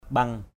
/ɓʌŋ/